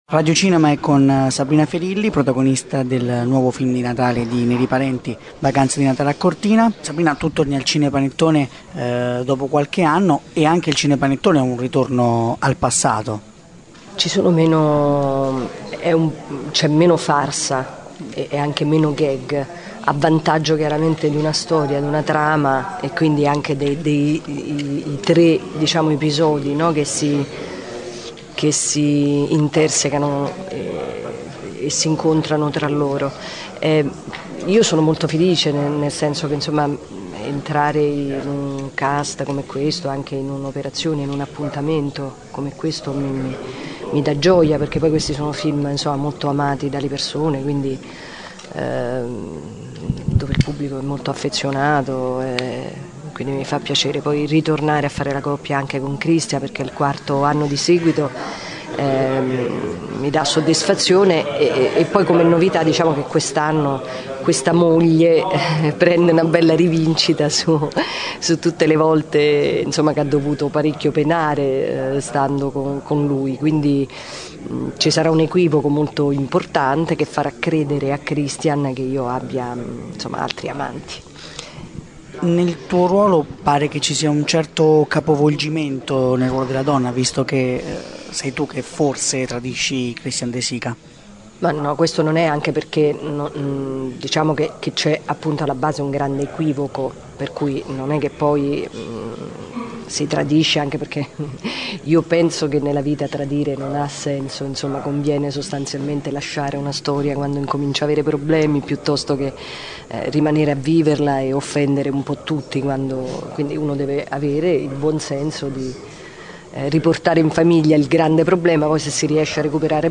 podcast_Intervista_Sabrina_Ferilli_Vacanze_Di_Natale_A_Cortina.mp3